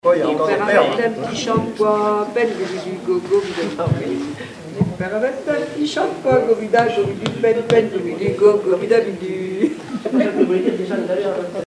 Chansons, formulettes enfantines
Pièce musicale inédite